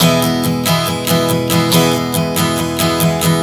Strum 140 E 02.wav